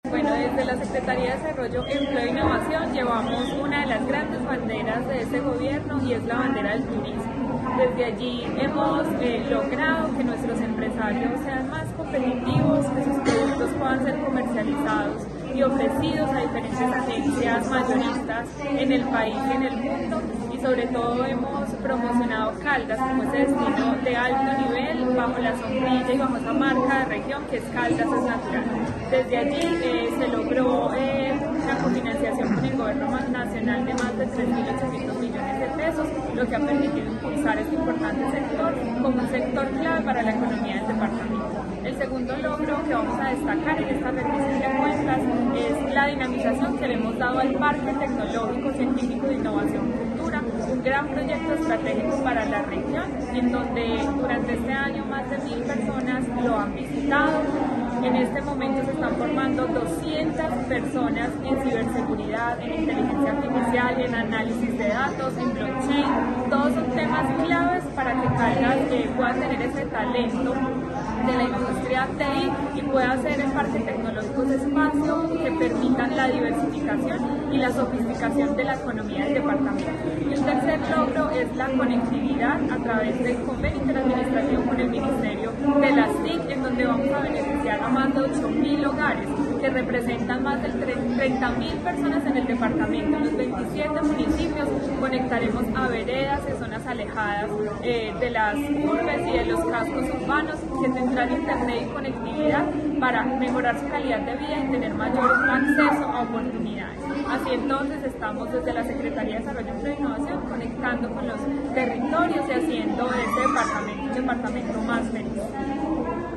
Daissy Lorena Alzate, secretaria de Desarrollo, Empleo e Innovación de Caldas.